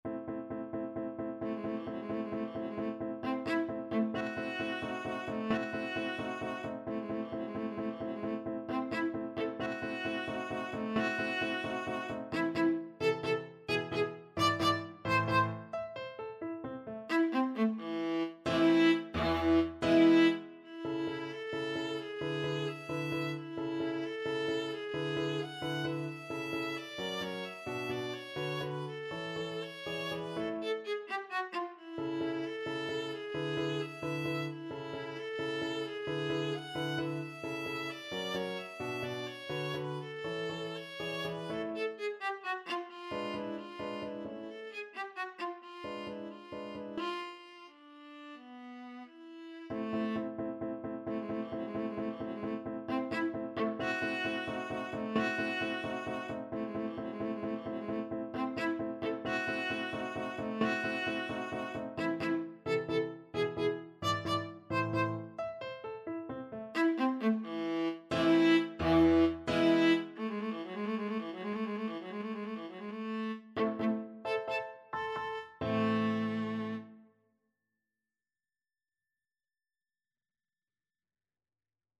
Viola
3/8 (View more 3/8 Music)
A minor (Sounding Pitch) (View more A minor Music for Viola )
Allegro con brio (.=104) .=88 (View more music marked Allegro)
Classical (View more Classical Viola Music)